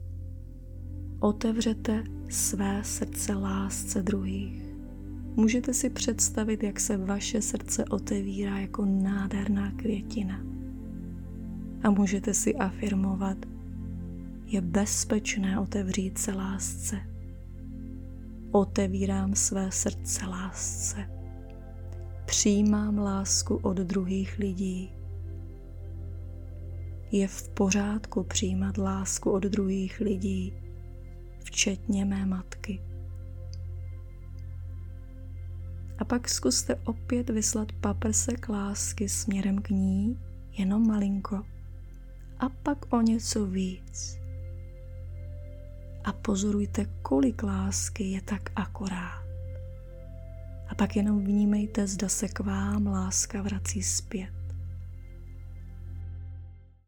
Přehrajte si krátkou ukázku jedné z meditací ↓ UKÁZKA Meditace mateřské zranění Ukázka meditace na MP3
Ukazka-Meditace-materske-zraneni.mp3